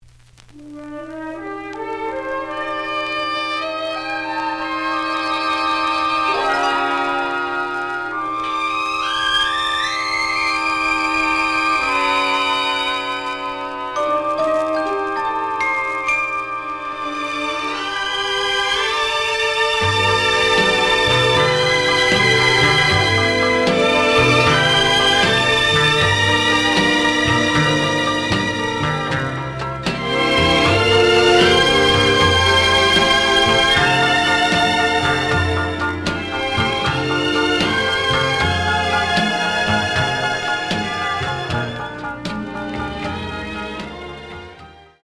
The audio sample is recorded from the actual item.
●Genre: Jazz Other
Slight noise on A side.